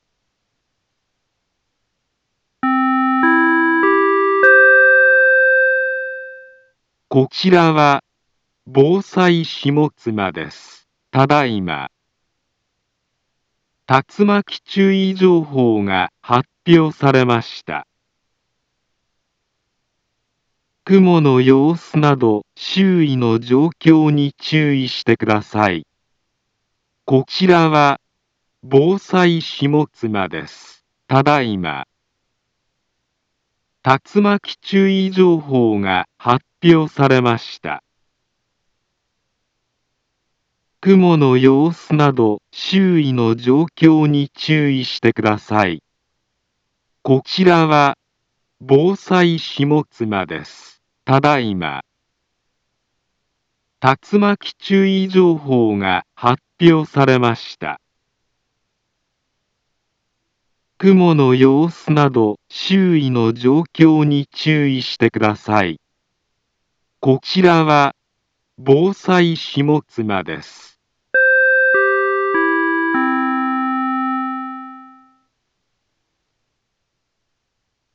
Back Home Ｊアラート情報 音声放送 再生 災害情報 カテゴリ：J-ALERT 登録日時：2024-07-20 15:19:20 インフォメーション：茨城県北部、南部は、竜巻などの激しい突風が発生しやすい気象状況になっています。